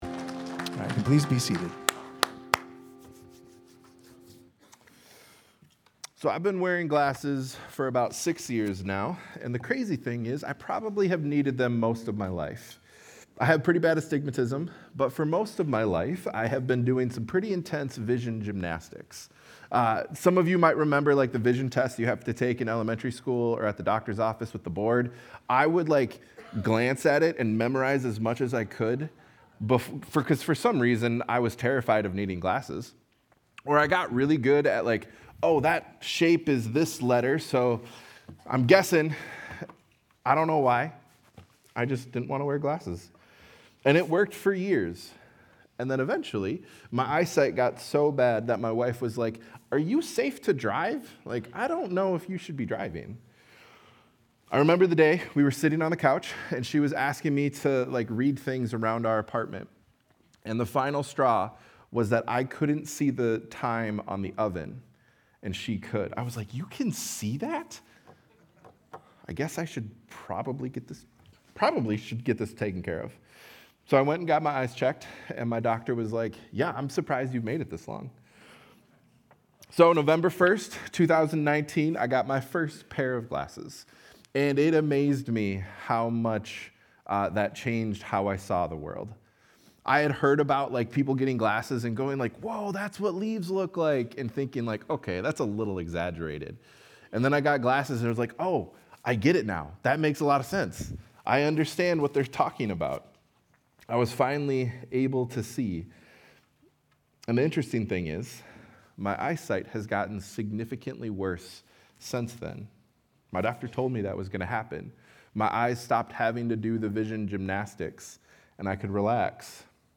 Sunday Sermon: 9-28-25